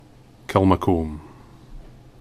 Kilmacolm (/ˌkɪlməˈklm/